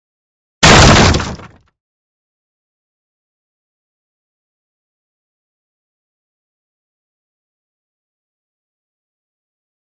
دانلود آهنگ شکستن در 2 از افکت صوتی اشیاء
جلوه های صوتی
دانلود صدای شکستن در 2 از ساعد نیوز با لینک مستقیم و کیفیت بالا